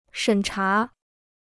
审查 (shěn chá): ตรวจสอบ; สอบสวน.